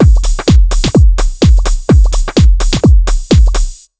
127BEAT6 7-R.wav